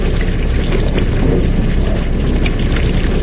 techage_reboiler.ogg